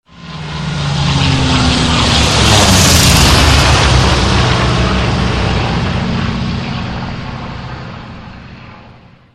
samolet_24801.mp3